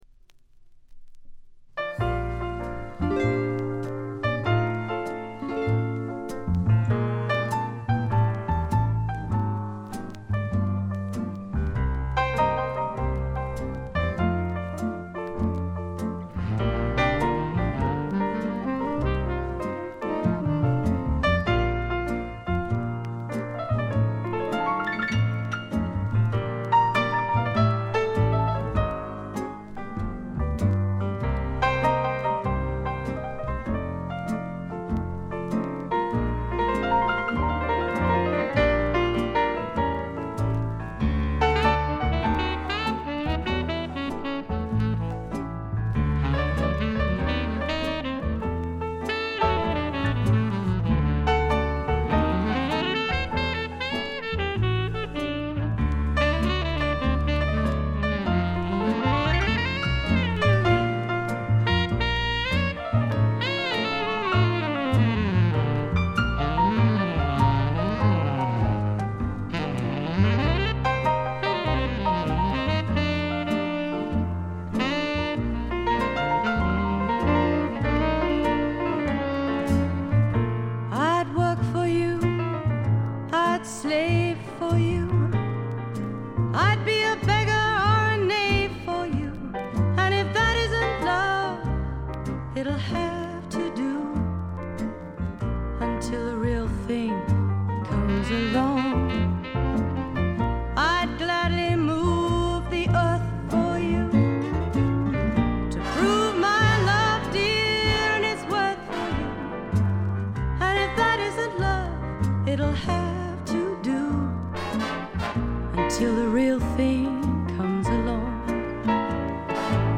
ところどころでチリプチがやや目立ちますが凶悪なノイズはありません。
1stのようないかにもな英国フォークらしさは影を潜め、オールドタイミーなアメリカンミュージック風味が加わってきています。
試聴曲は現品からの取り込み音源です。
vocals, piano, acoustic guitar